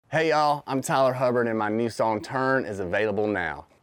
LINER-Tyler-Hubbard-Turn-1.mp3